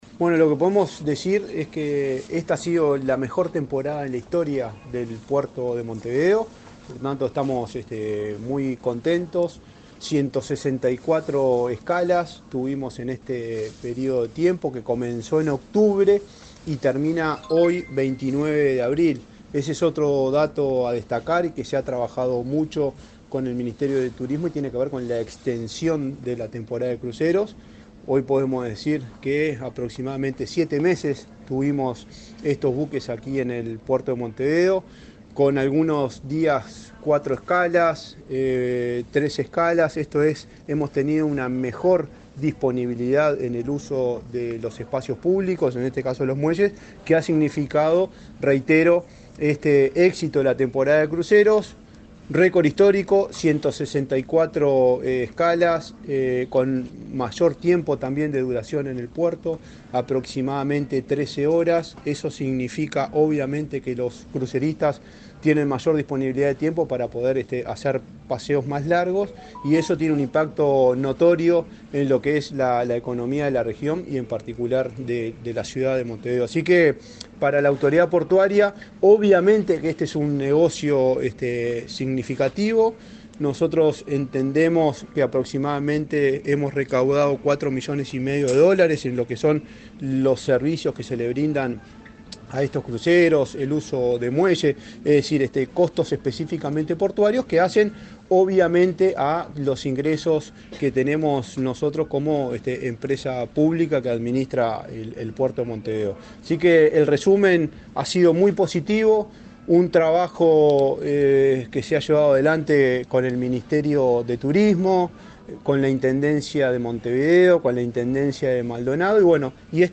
Declaraciones del presidente de la ANP, Juan Curbelo
Este viernes 29 en el puerto de Montevideo, el presidente de la Administración Nacional de Puertos (ANP), Juan Curbelo, informó a la prensa acerca de